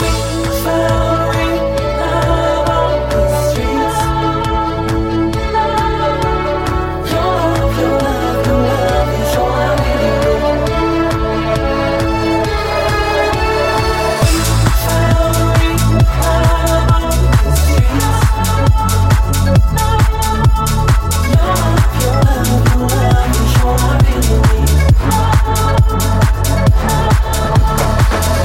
Genere: house, chill house,remix